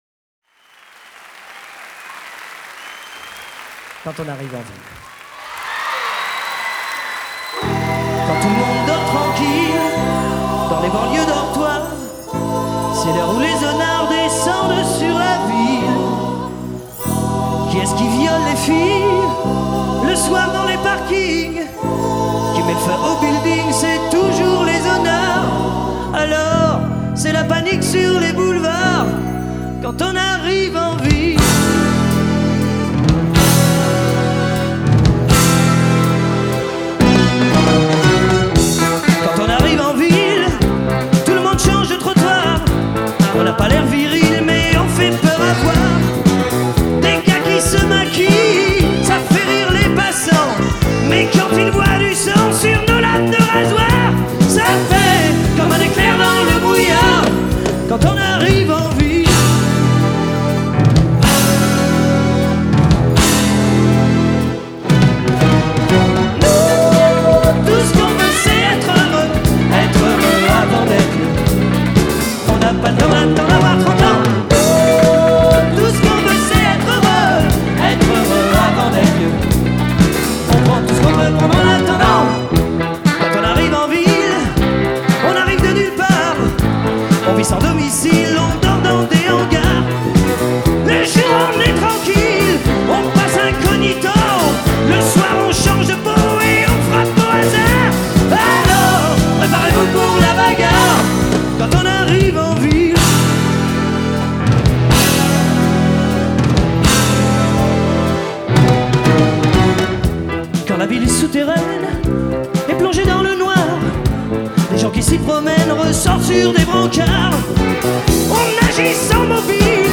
Live à l'Olympia _ 1981